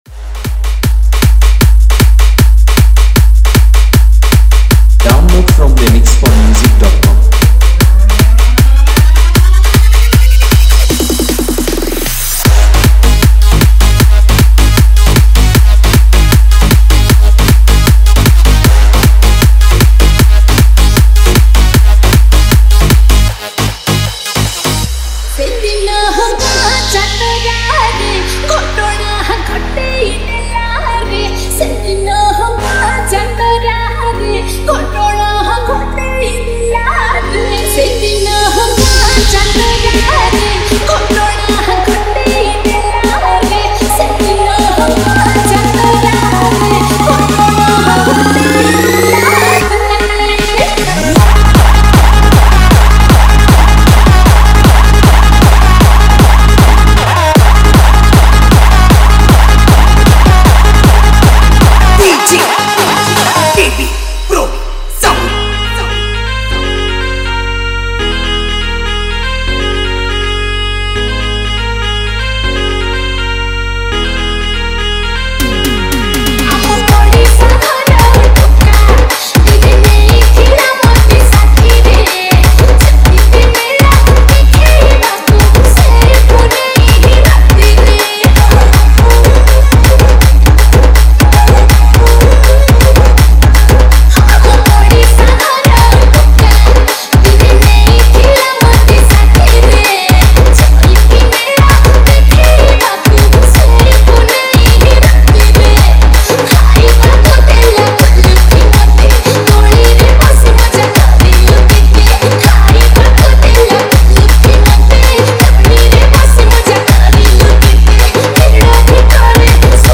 Category : Odia Remix Song